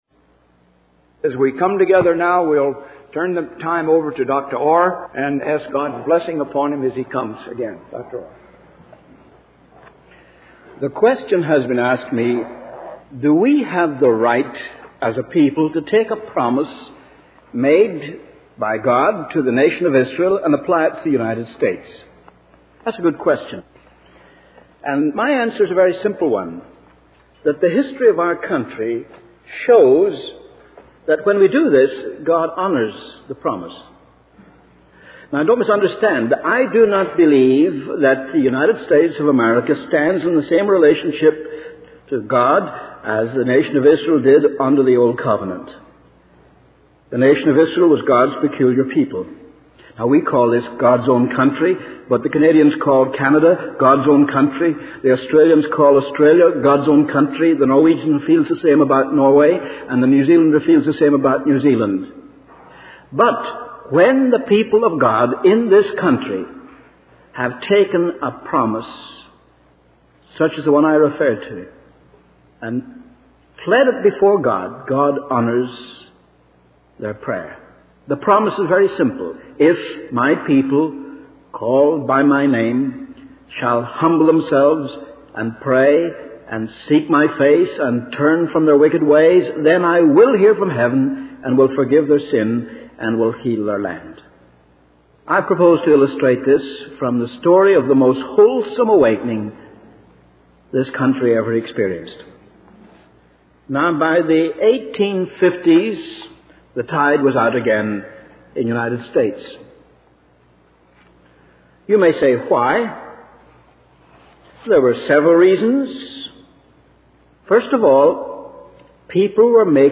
In this sermon, the speaker discusses a powerful revival that took place in Chicago in 1858.